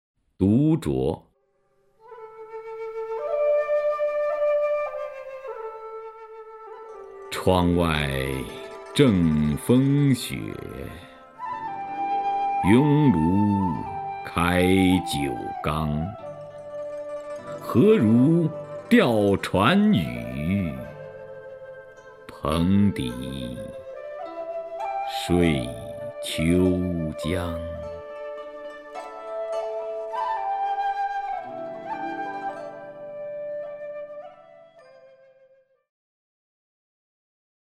徐涛朗诵：《独酌》(（唐）杜牧) （唐）杜牧 名家朗诵欣赏徐涛 语文PLUS